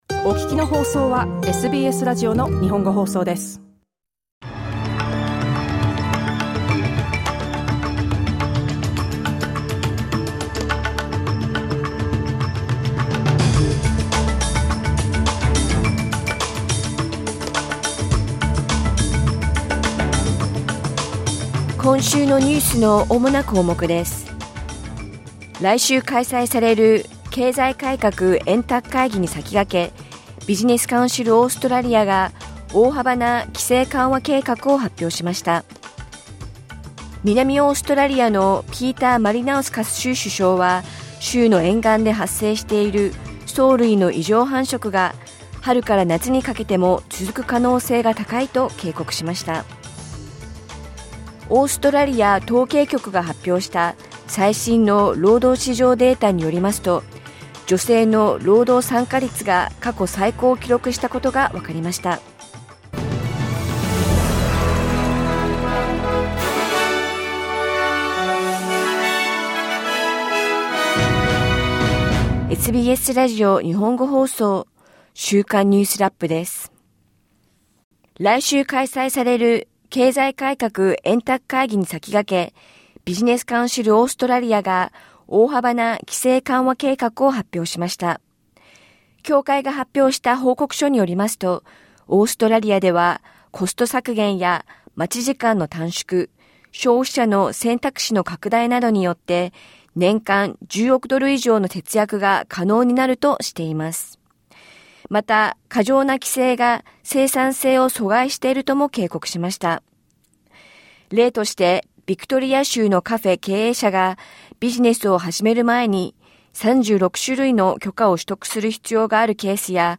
来週開催される経済改革円卓会議に先駆け、ビジネス・カウンシル・オーストラリアが大幅な規制緩和計画を発表しました。南オーストラリアのピーター・マリナウスカス州首相は、州の沿岸で発生している藻類の異常繁殖が、春から夏にかけても続く可能性が高いと警告しました。オーストラリア統計局が発表した最新の労働市場のデータによりますと、女性の労働参加率が過去最高を記録したことがわかりました。1週間を振り返るニュースラップです。